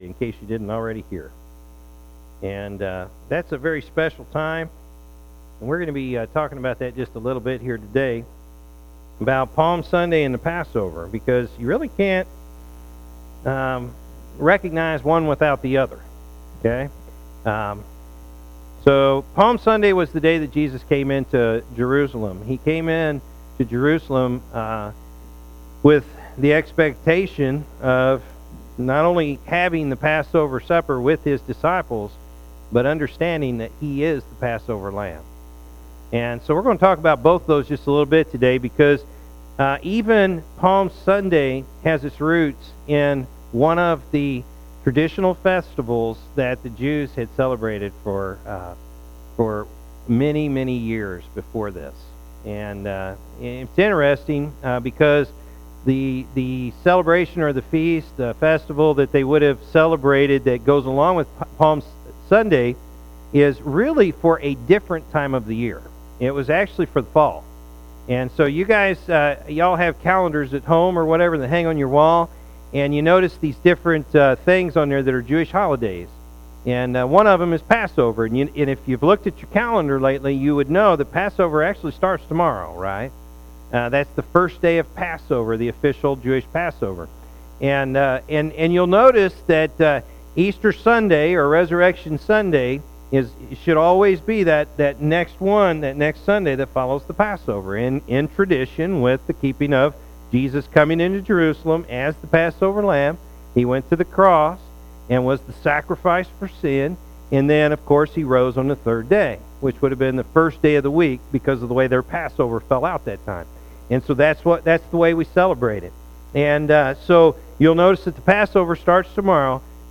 April-9-2017-morning-service.mp3